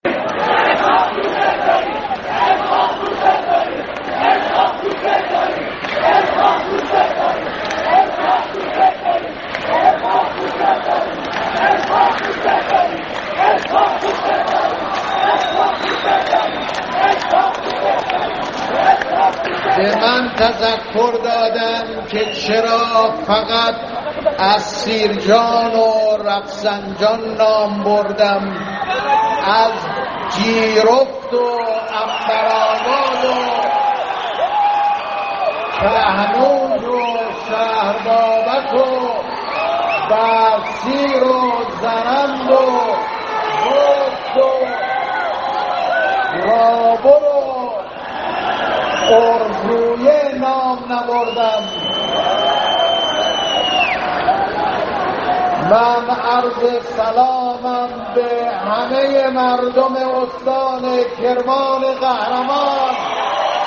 به گزارش خبرنگار سیاسی خبرگزاری تسنیم، حسن روحانی کاندیدای دوازدهمین دوره انتخابات ریاست‌جمهوری صبح امروز (شنبه 9 اردیبهشت) برای سخنرانی به شهر کرمان رفت.
در این سخنرانی مردم با سر دادن شعار "اسحاق، دوستت داریم" و حمایت از معاون اول او اسحاق جهانگیری از روحانی استقبال کردند.